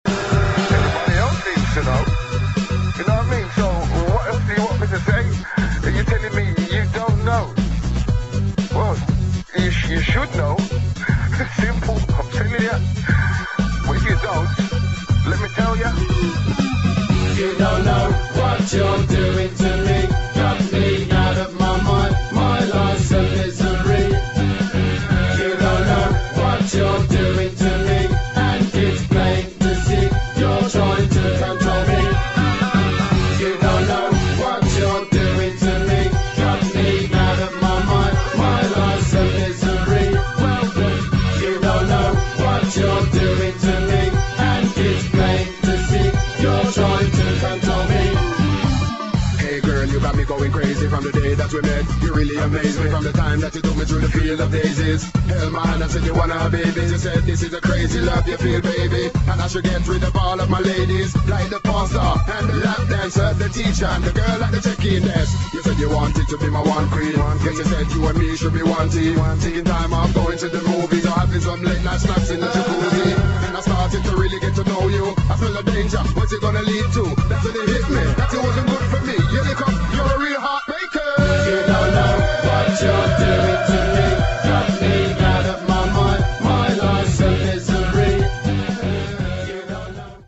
[ HOUSE | ELECTRO | BREAKS ]